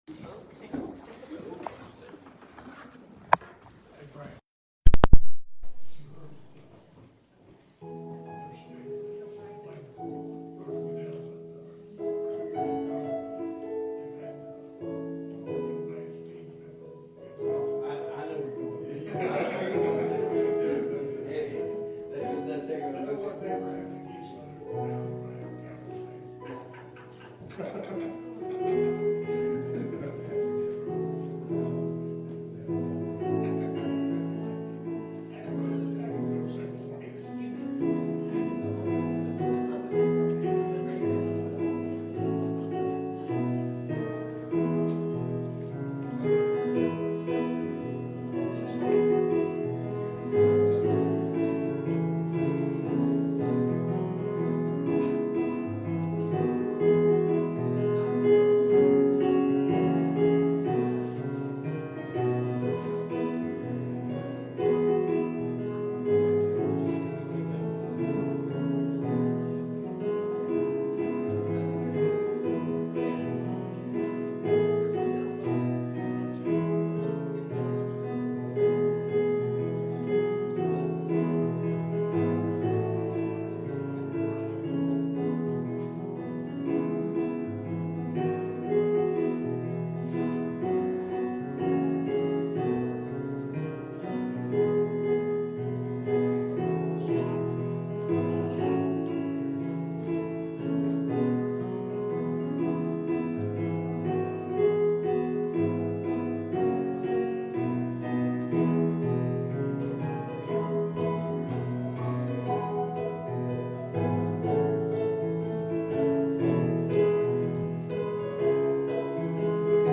What Every Good Pastor Must Do | SermonAudio Broadcaster is Live View the Live Stream Share this sermon Disabled by adblocker Copy URL Copied!